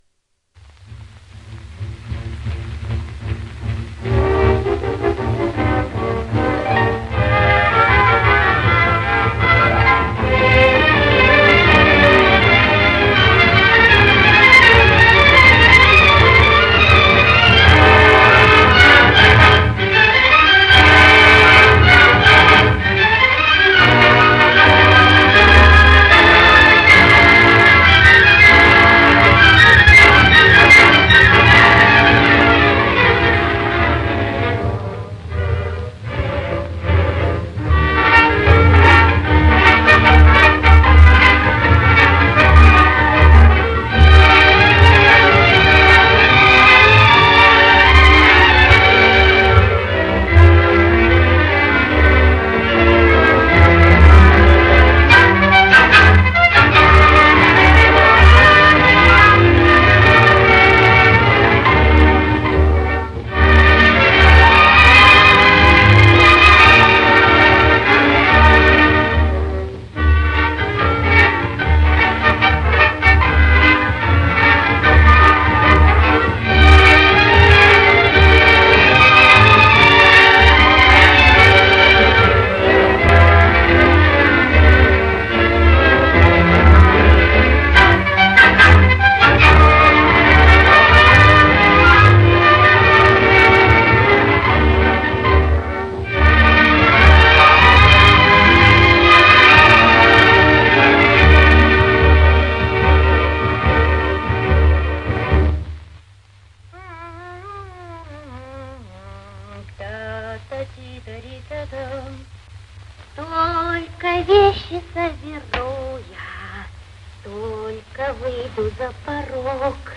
Одна из лучших лирических песен довоенного периода